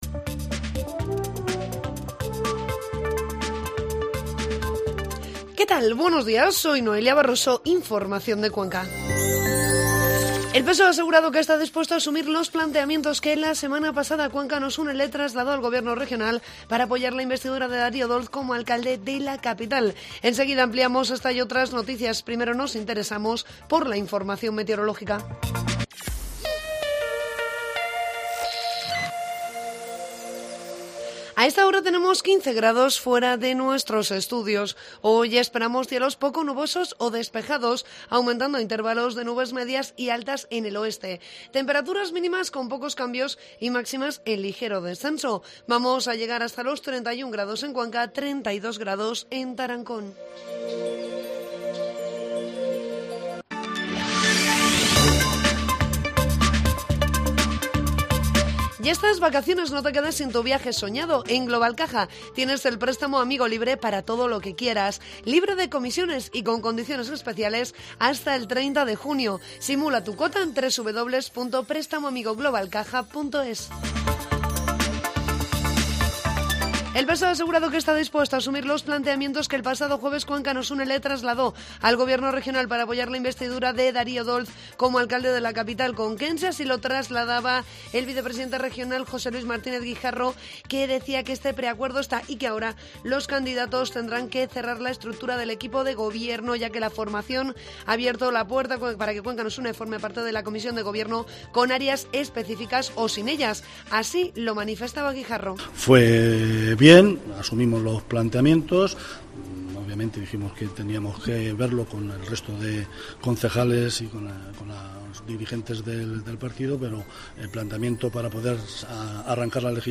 Informativo matinal COPE Cuenca 4 de junio